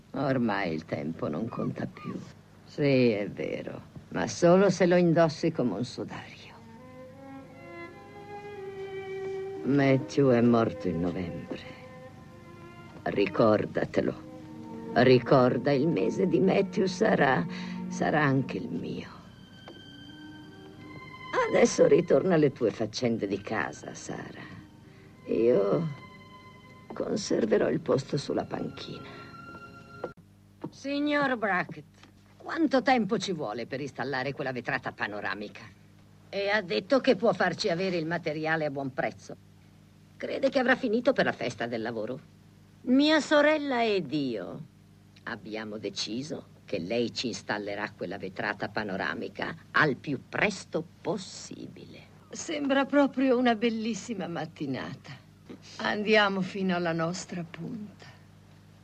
nel film "Le balene d'agosto", in cui doppia Bette Davis.